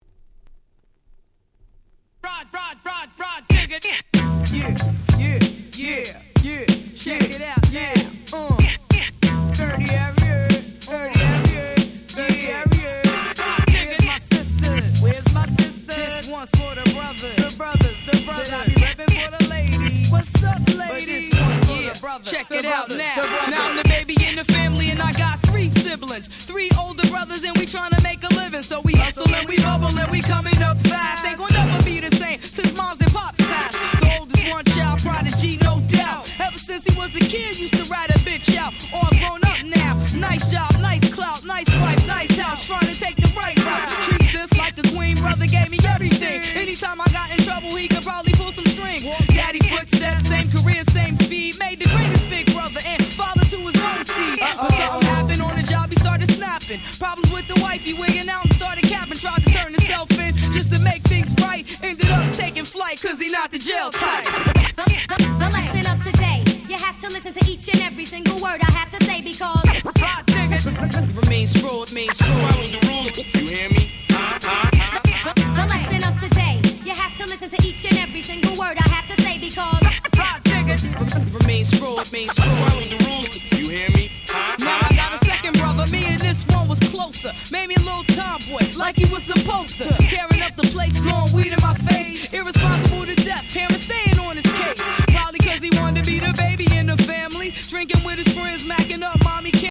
【Media】Vinyl 12'' Single
00' Super Hit Hip Hop !!